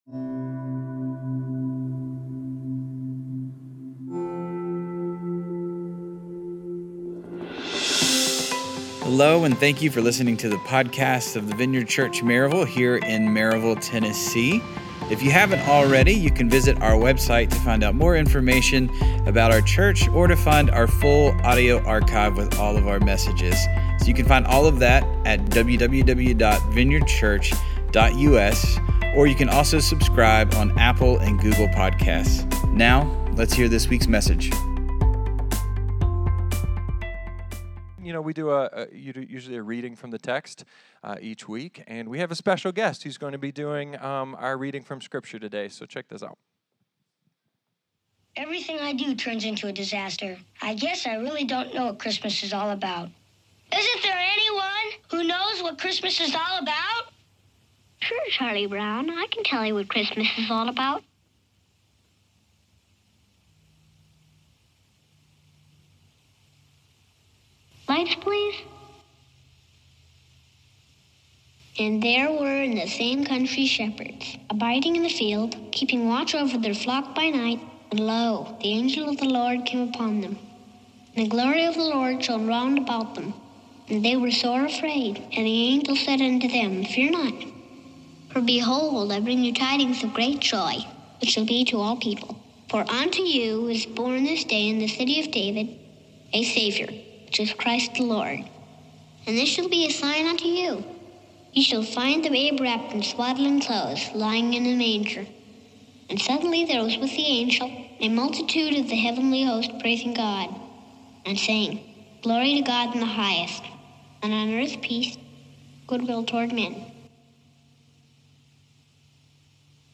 A sermon wrestling with the angel’s promise of ‘Peace on Earth’ by way of a manger— cause it’s been 2,000 years and that doesn’t seem to be working out.